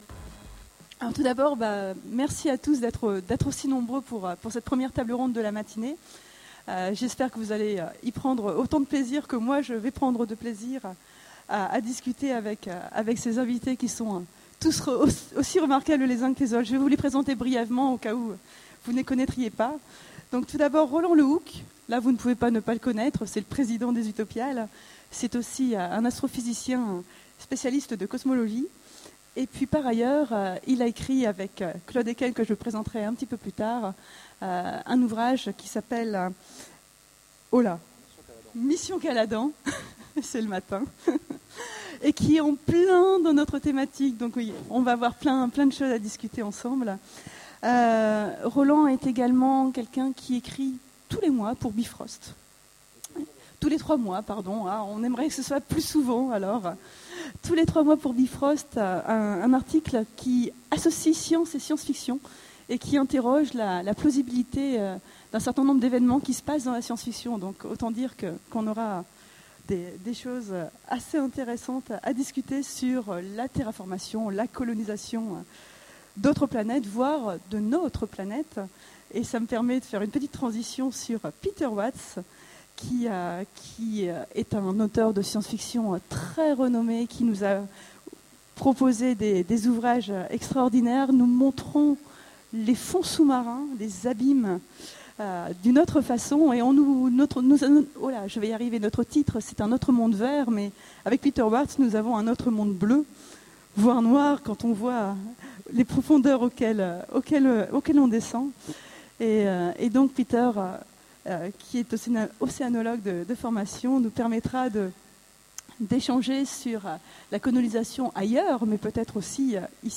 Utopiales 13 : Conférence Un autre monde vert ? Colonisation, terraformation et exploitation du système solaire